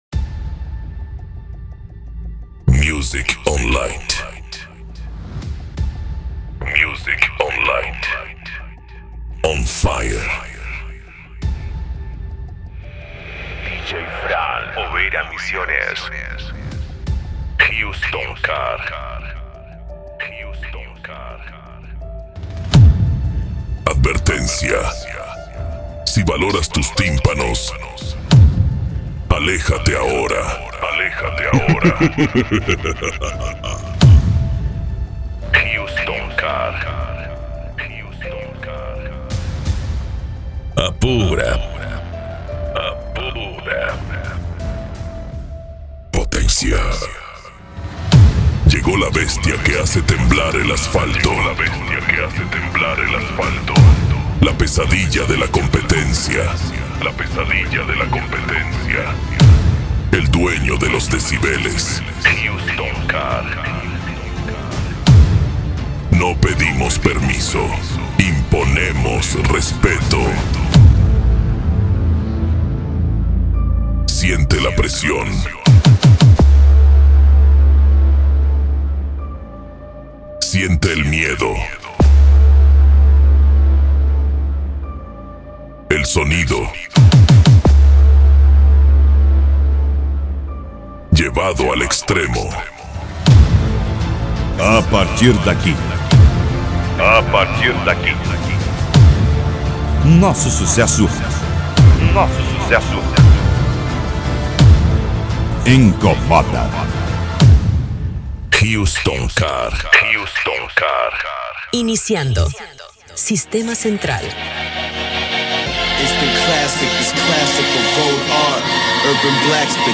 Bass
Cumbia
Funk
Musica Electronica
Remix